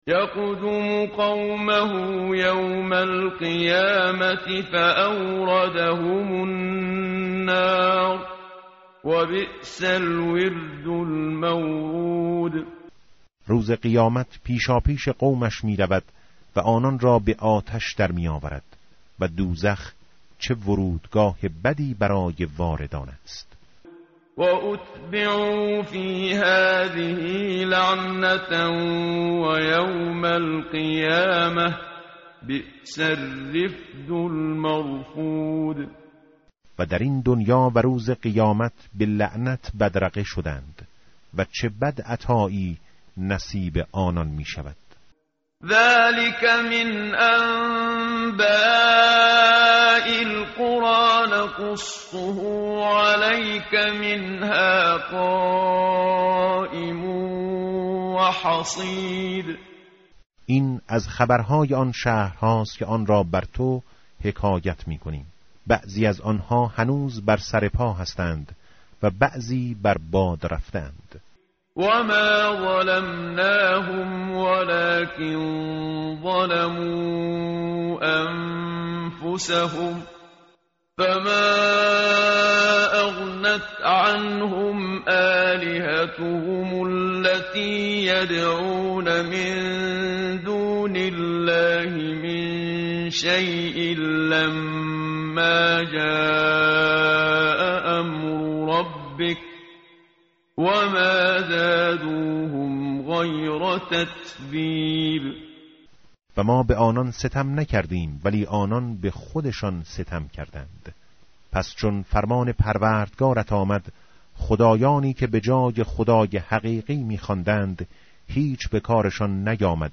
tartil_menshavi va tarjome_Page_233.mp3